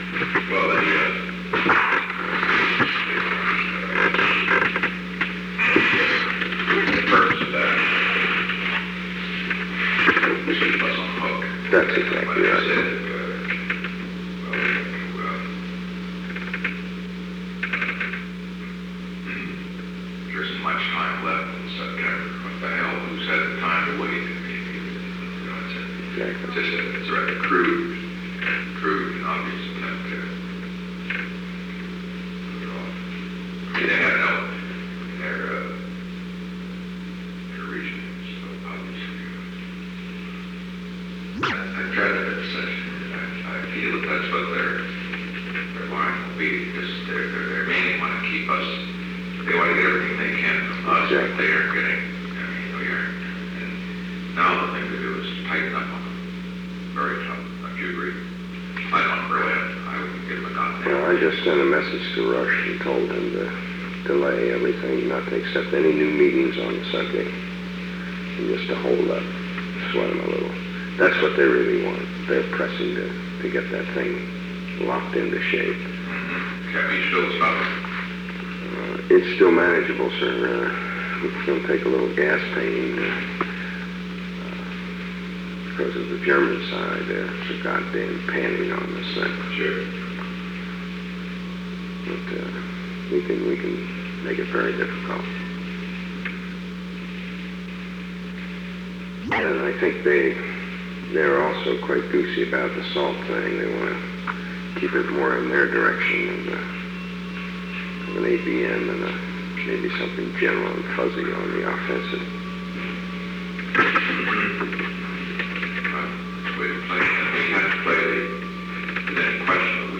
Location: Oval Office
Secret White House Tapes